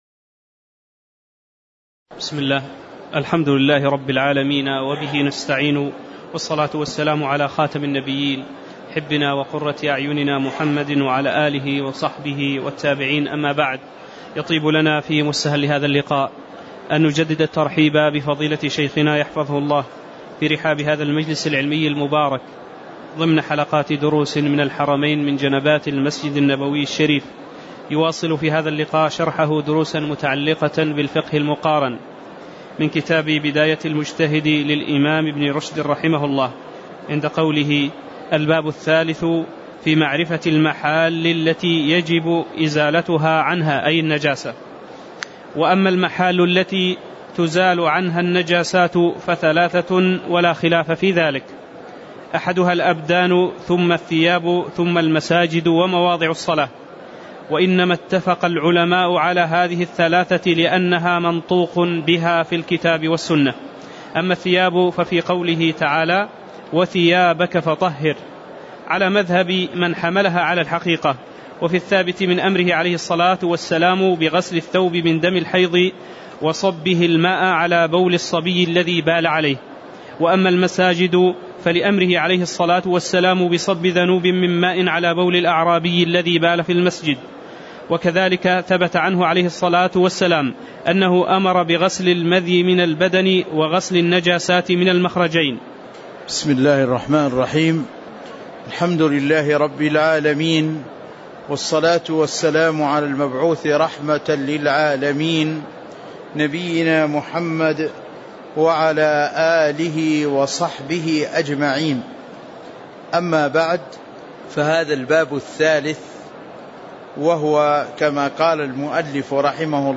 تاريخ النشر ٢٥ رجب ١٤٤٠ هـ المكان: المسجد النبوي الشيخ